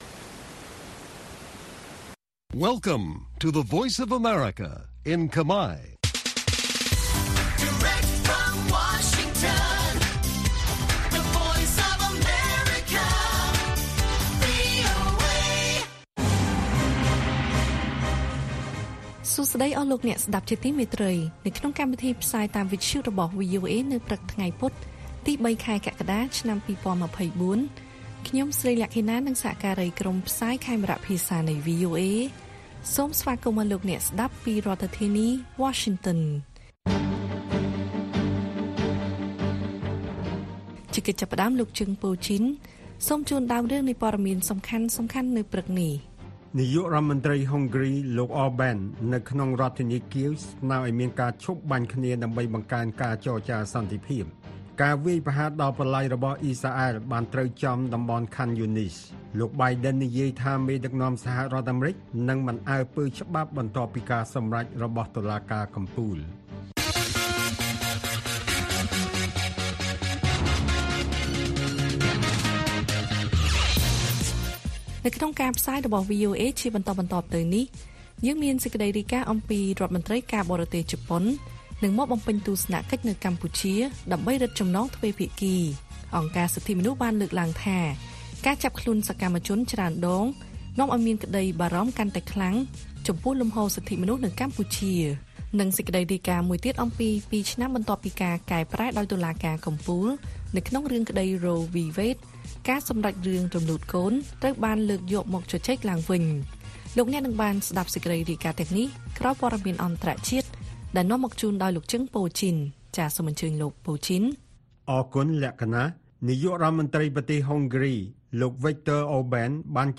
ព័ត៌មានពេលព្រឹក ៣ កក្កដា៖ រដ្ឋមន្ត្រីការបរទេសជប៉ុននឹងមកបំពេញទស្សនកិច្ចនៅកម្ពុជាដើម្បីរឹតចំណងទ្វេភាគី